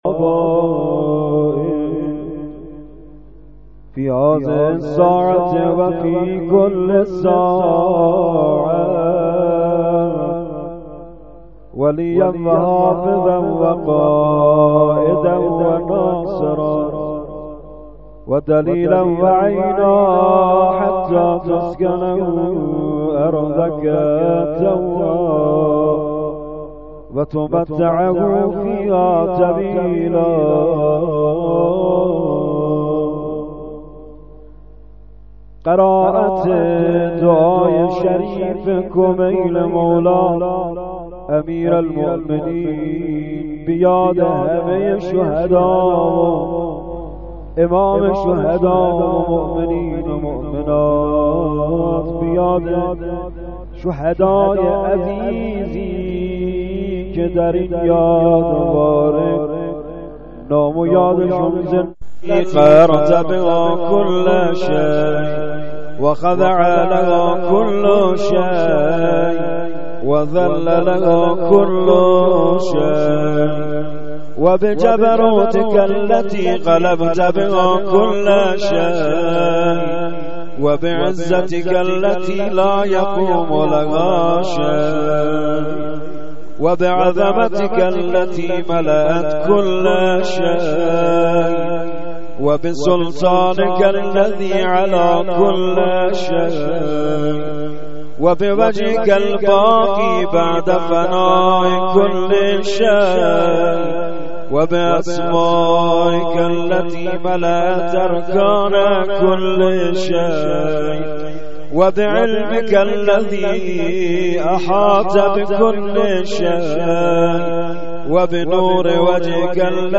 دعای کمیل یادواری شهدای زنگی آباد
مداحی
doaye-komeyl-yadvare-1393.mp3